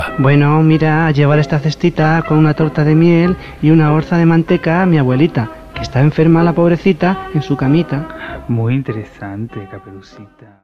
Versió radiofònica del conte "La caperutxeta vermella" de Charles Perrault amb motiu dels 300 anys de la seva publicació.